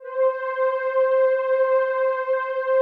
PAD 47-5.wav